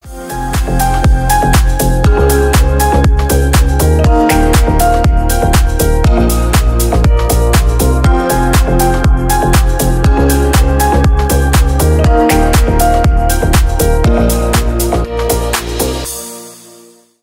Рингтоны Новинки 2023-2024, Зарубежные рингтоны